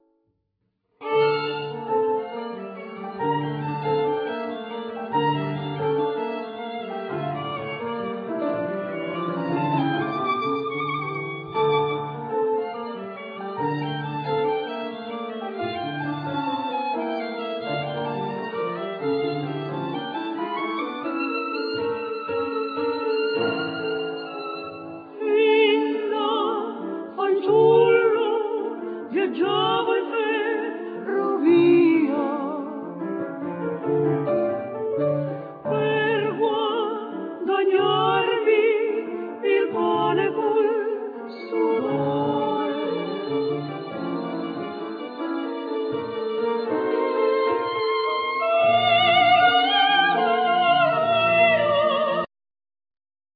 Vocal
Violin
Cello
Piano
Chorus
Percussions
Keyboards
Tambura,Violin,Mandolin
Flute,Clarinetto,Percussions
Harmonica
Guitar